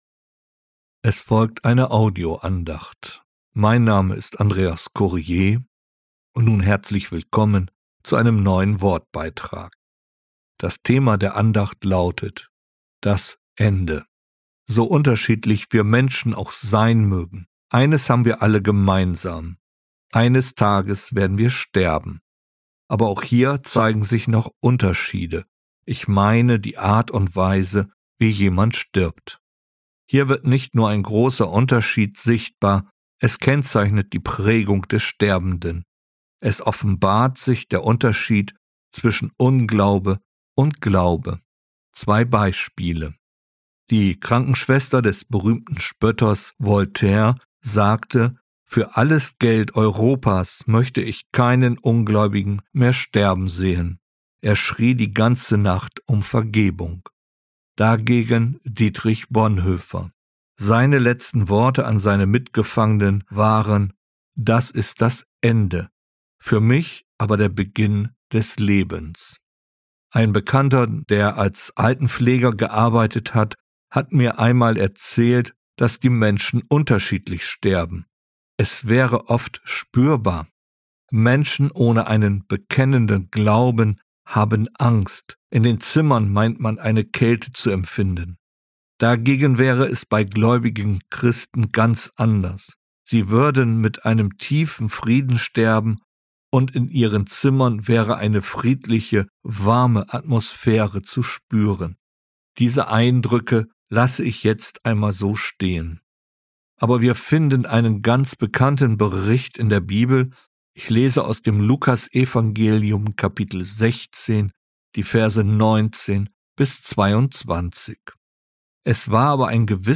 Das Ende, eine Audio-Andacht